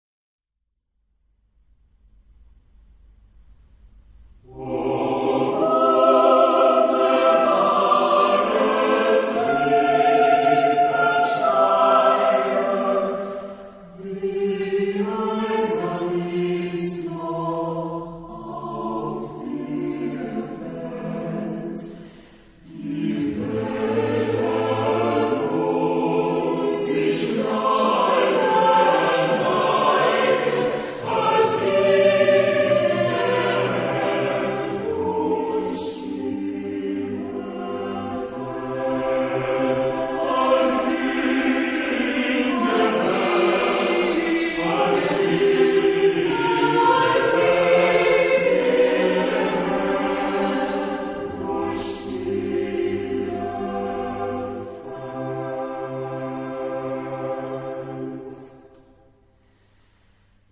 Genre-Style-Forme : Romantique ; Profane ; Lied
Type de choeur : SATB  (4 voix mixtes )
Tonalité : si bémol majeur